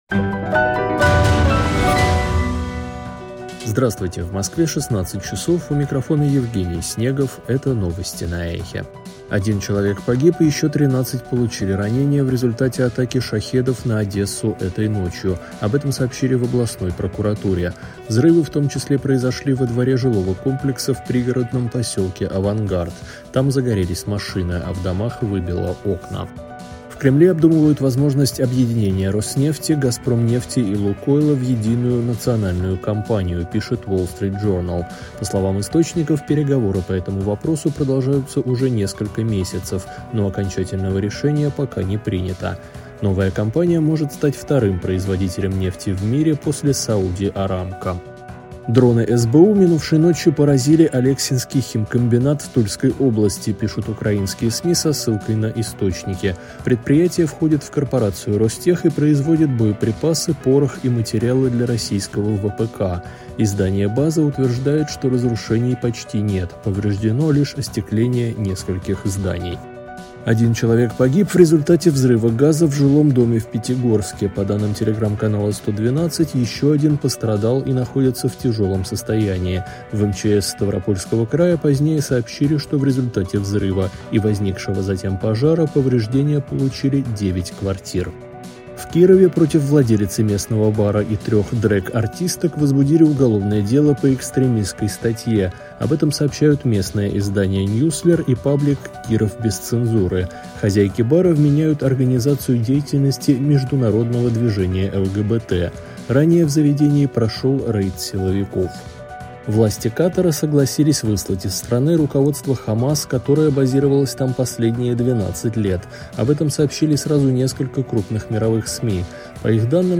Слушайте свежий выпуск новостей «Эха».
Новости